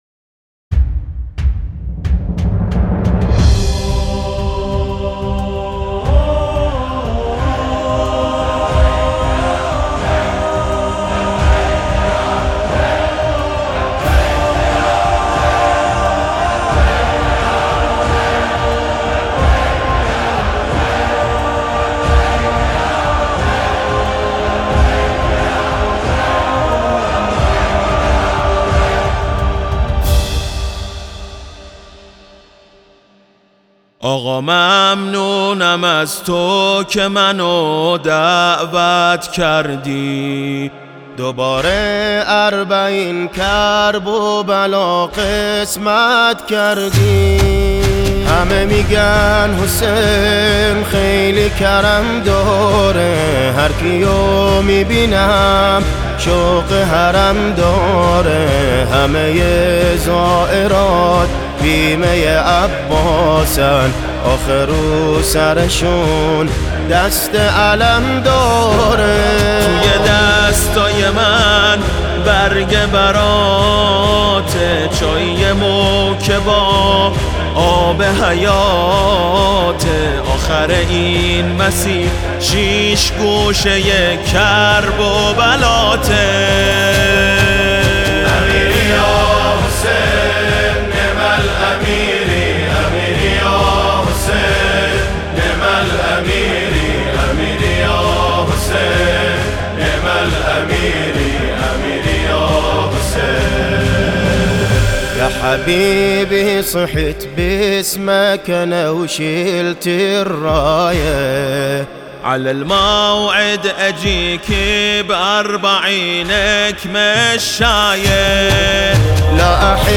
امیری یاحسین | صدا استودیویی به مناسبت پیاده روی اربعین | شور